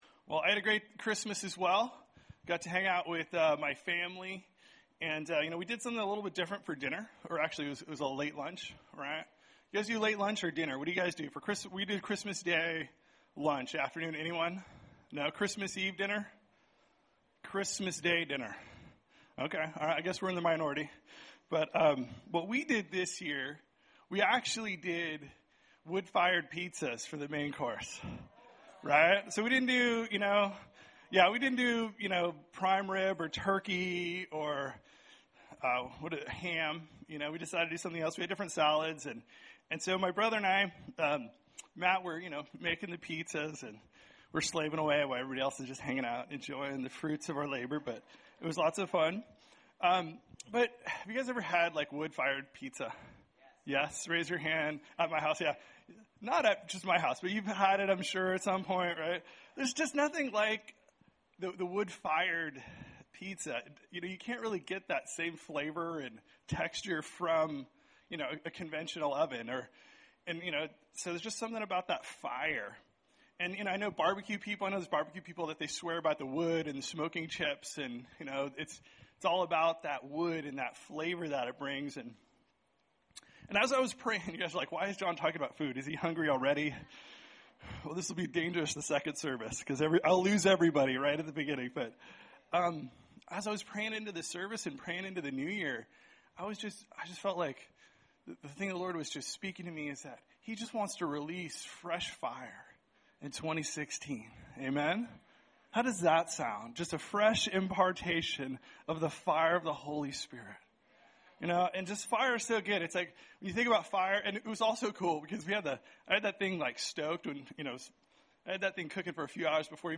Recorded at New Life Christian Center, Sunday, Dec 27, 2015 at 9 AM.